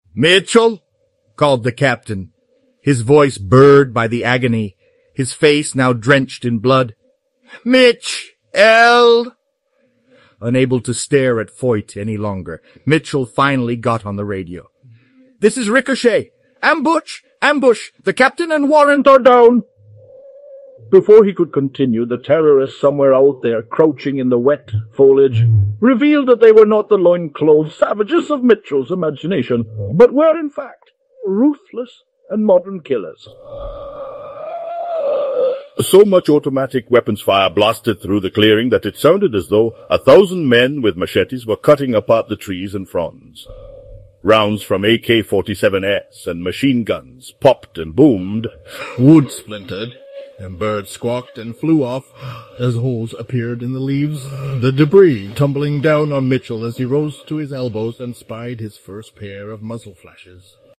I am changing accents in this recording from Eleven Labs.